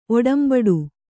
♪ oḍambaḍu